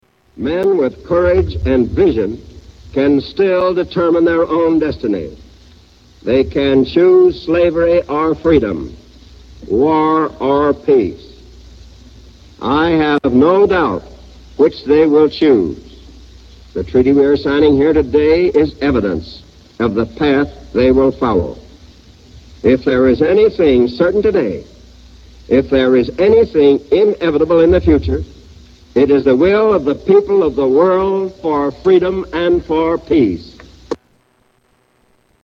Tags: History Presidents Of the U. S. President Harry S. Truman Speeches